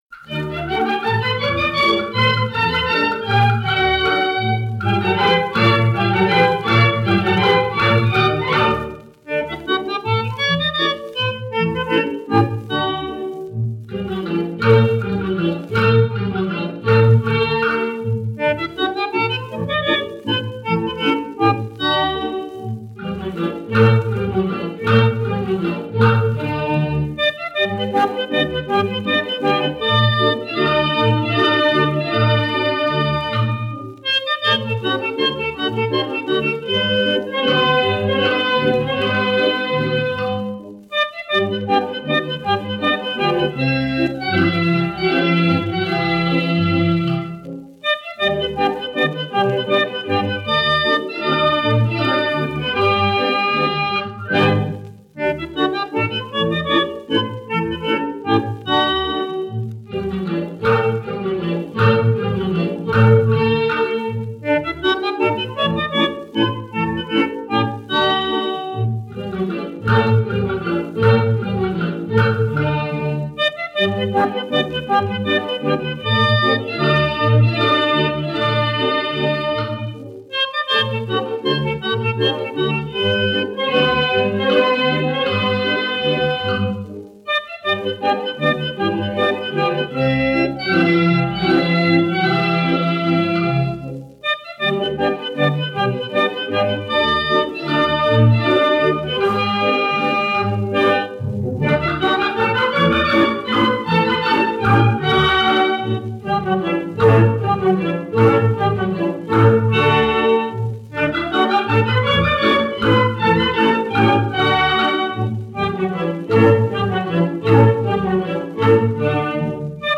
Ranchera.
78 rpm